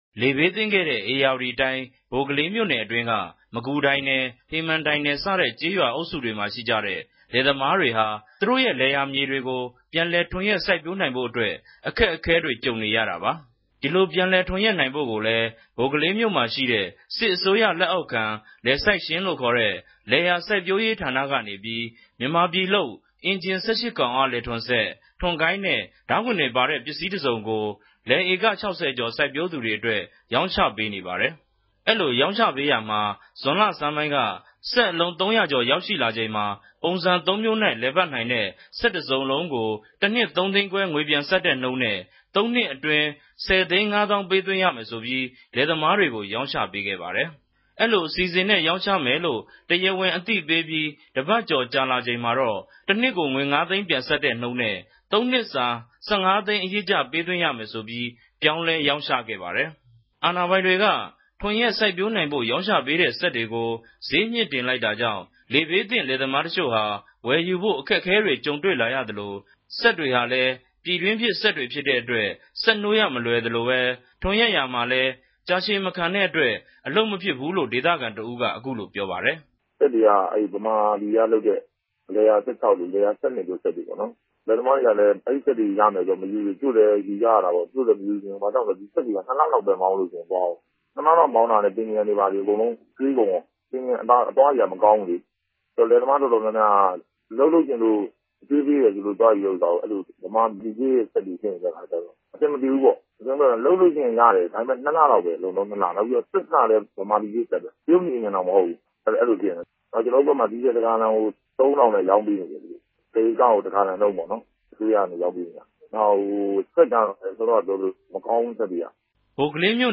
သတင်းပေးပိုႚႛခဵက်။